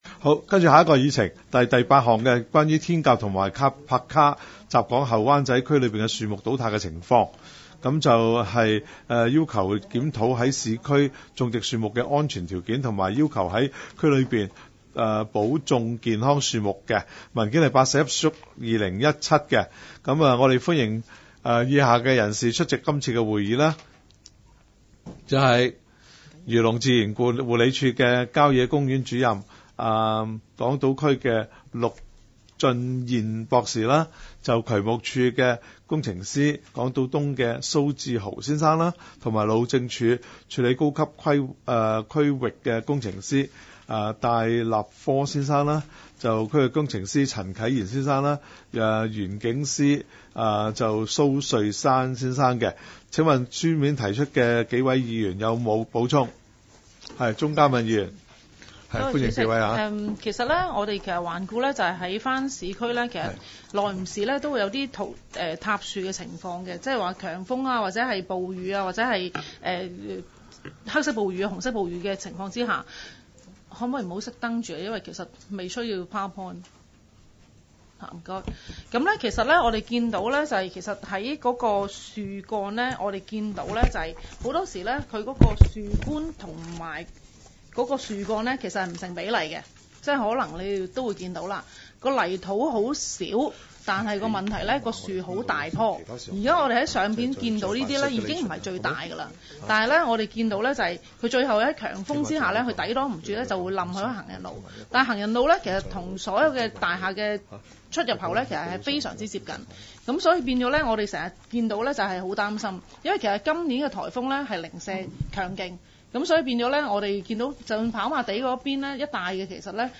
区议会大会的录音记录
湾仔区议会第十二次会议